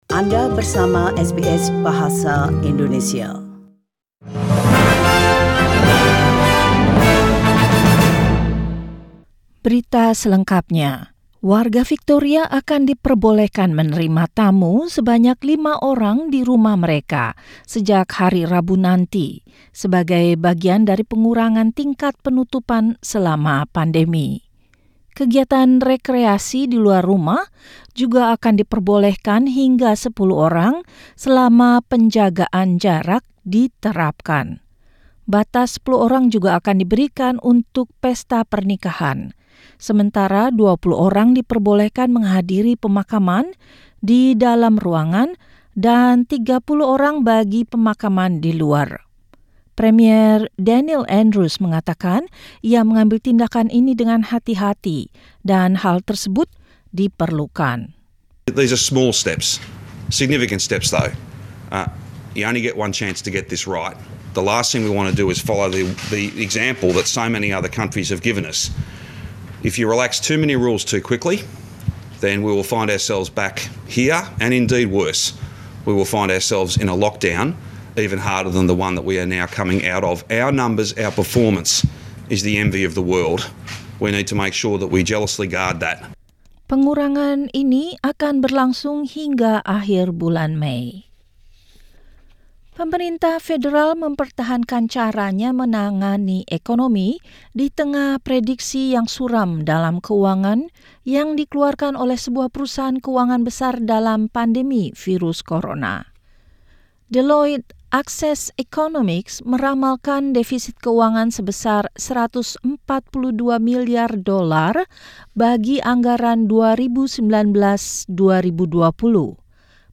SBS Radio News in Indonesian - 11 May 2020